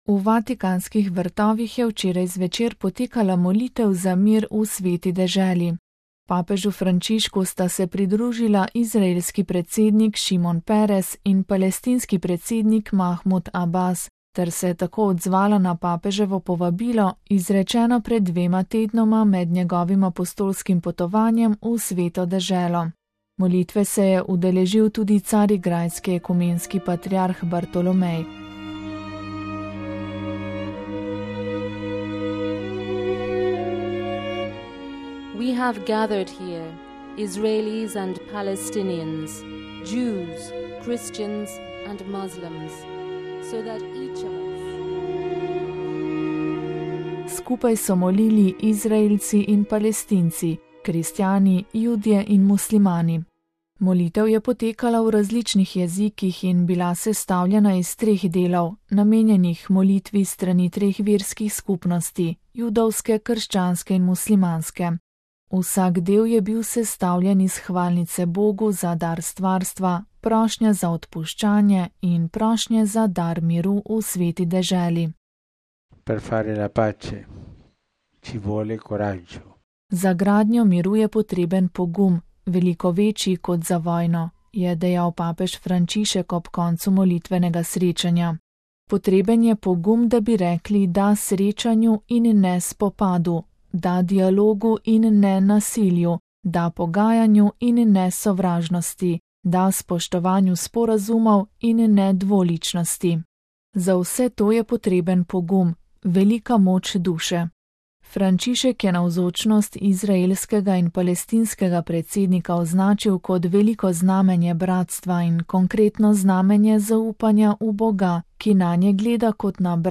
VATIKAN (ponedeljek, 9. junij 2014, RV) – V vatikanskih vrtovih je včeraj zvečer potekala molitev za mir v Sveti deželi.
Molitev je potekala v različnih jezikih in bila sestavljena iz treh delov, namenjenih molitvi s strani treh verskih skupnosti: judovske, krščanske in muslimanske.